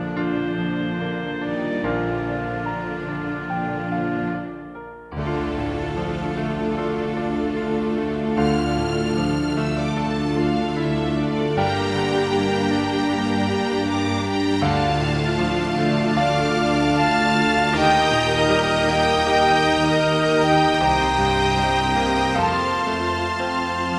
No Vocals At All Soundtracks 3:12 Buy £1.50